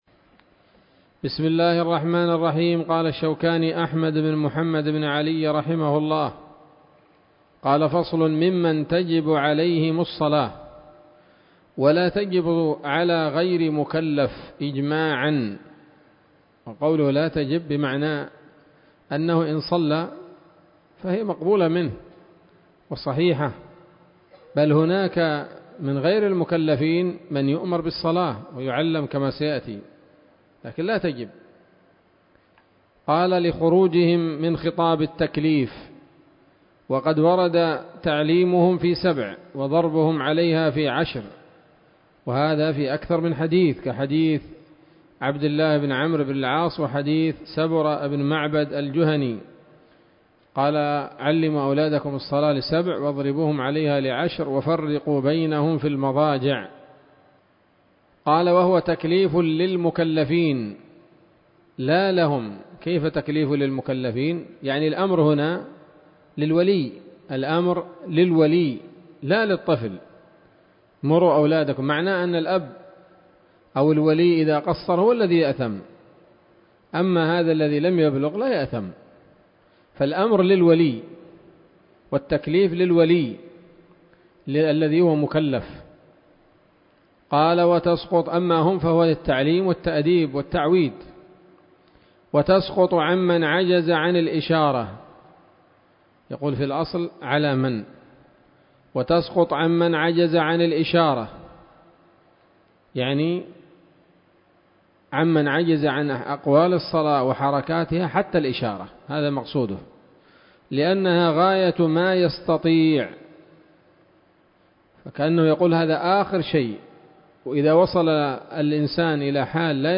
الدرس التاسع عشر من كتاب الصلاة من السموط الذهبية الحاوية للدرر البهية